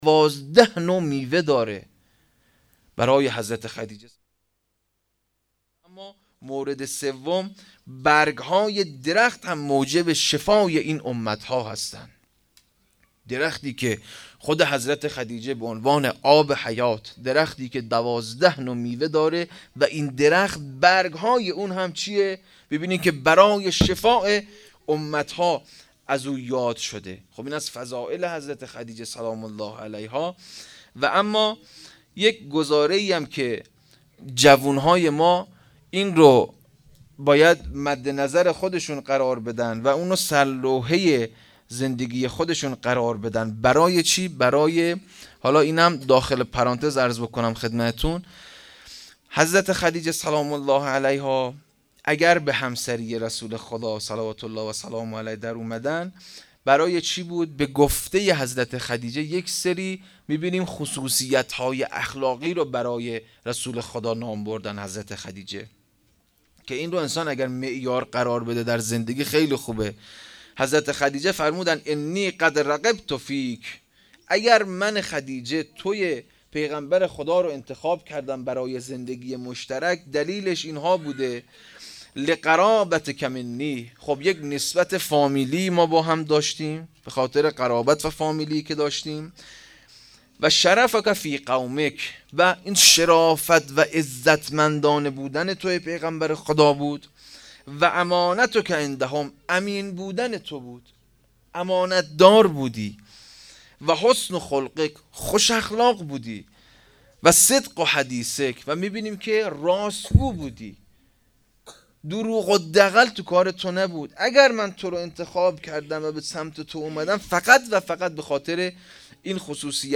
ویژه برنامه هفتگی هیئت در ماه مبارک رمضان-وفات حضرت خدیجه1403